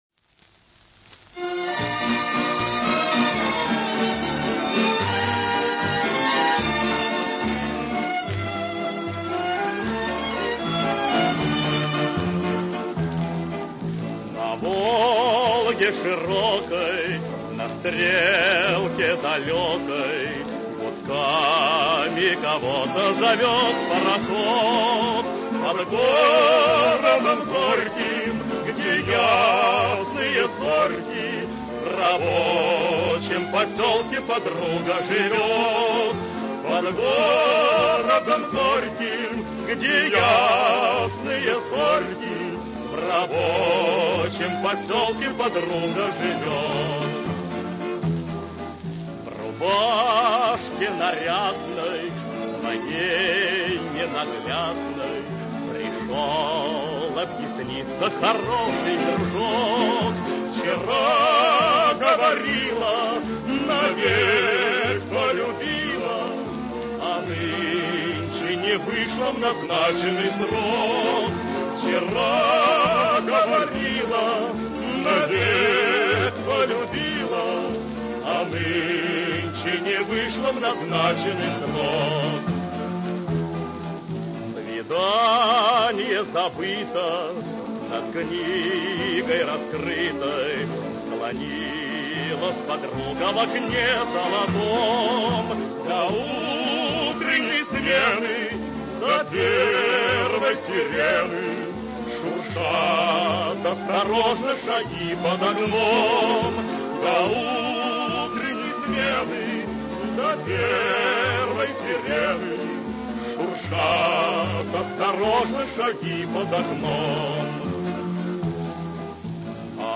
Красивая лирическая песня о Волге